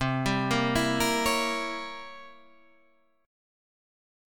C7b9 chord